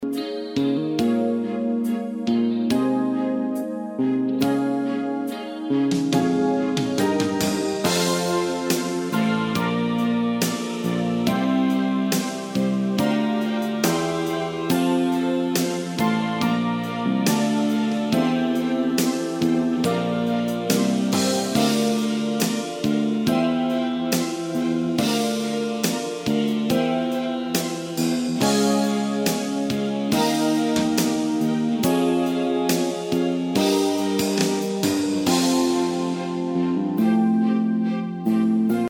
Original Key without Background Vocal